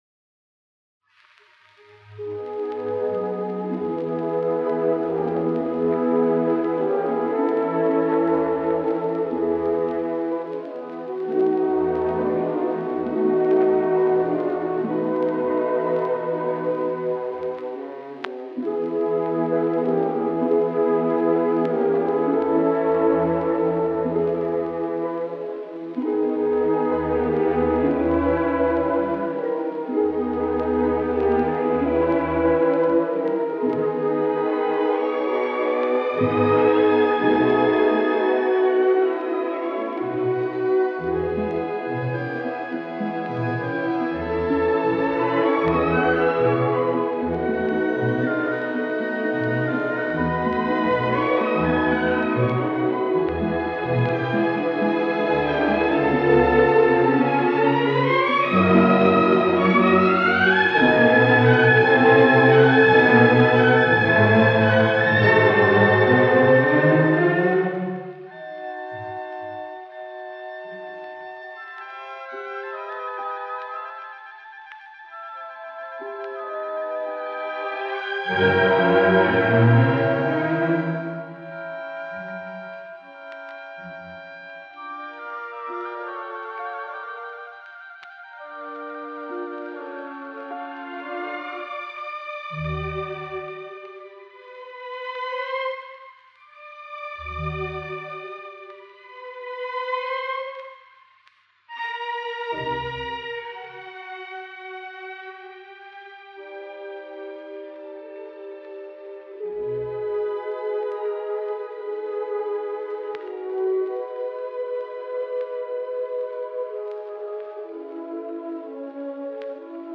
Los preludios . Poema sinfónico.